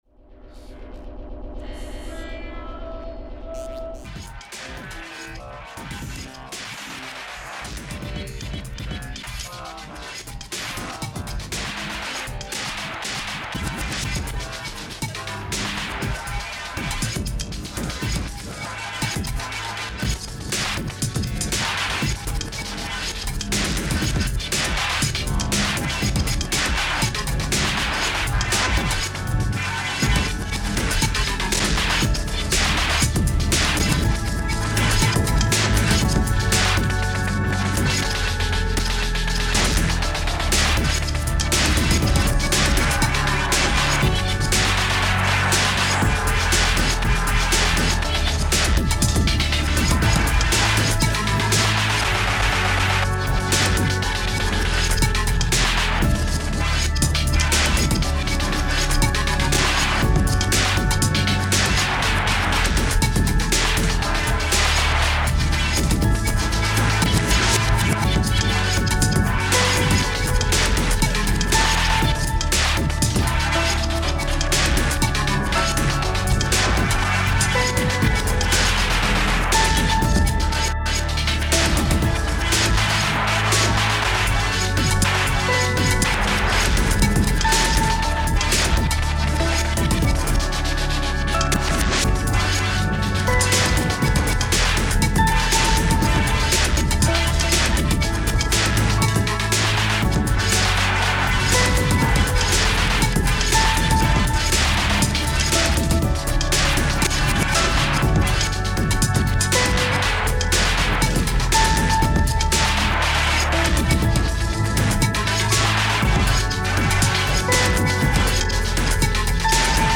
These are some of my old beats.